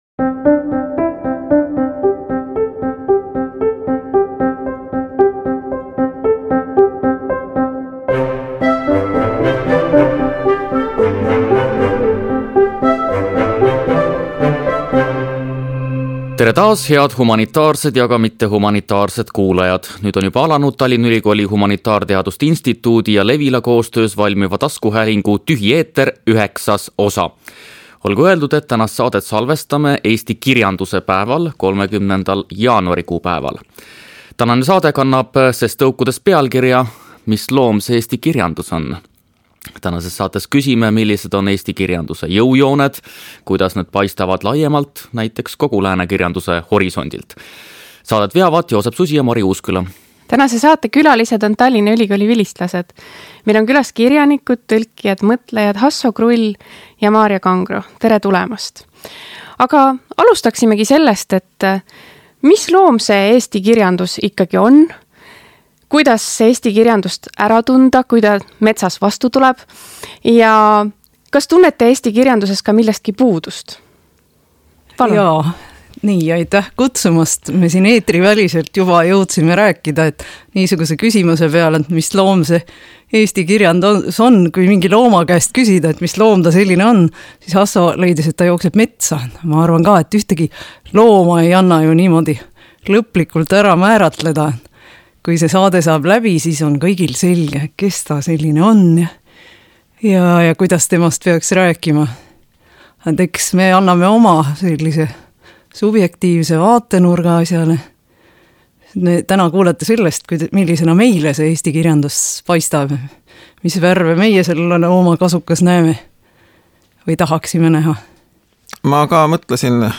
Tallinna Ülikooli Humanitaarteaduste instituudi ja Levila koostöös valmiva taskuhäälingu „TÜHI eeter” üheksas osa salvestati eesti kirjanduse päeval.